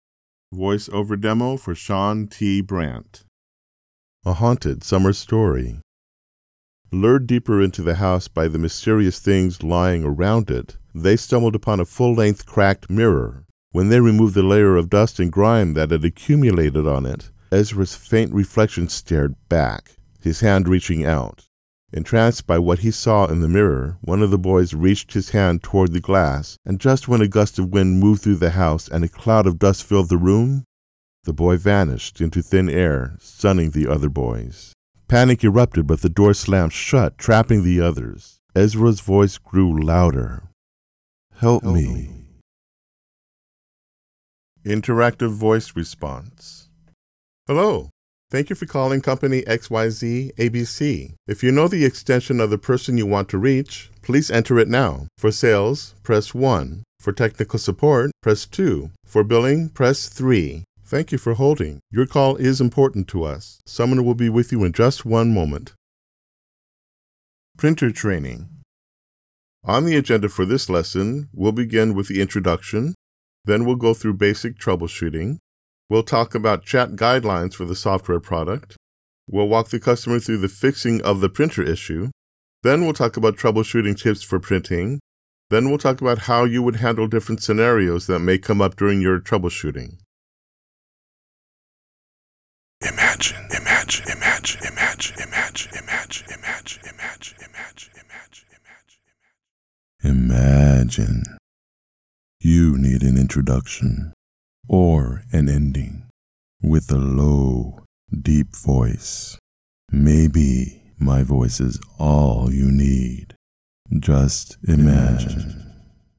Standard American Male accent